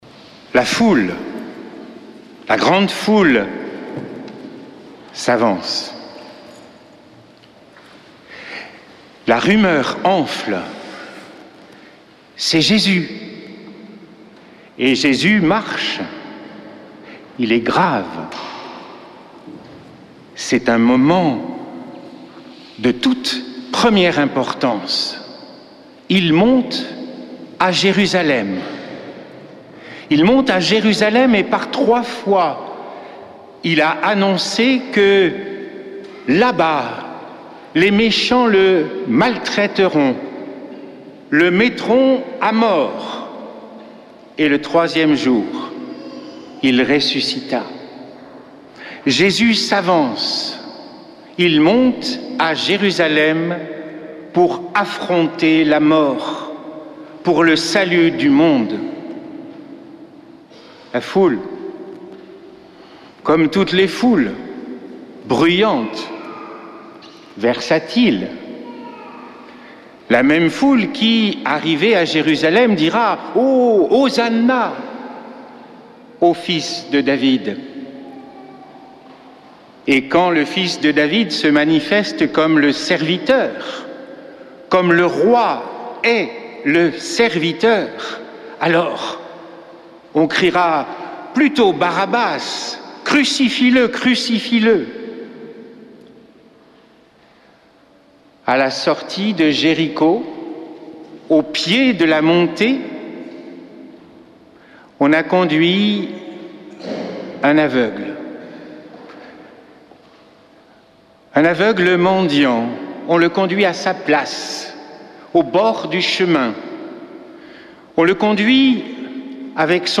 Messe depuis le couvent des Dominicains de Toulouse